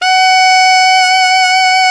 SAX ALTOFF0K.wav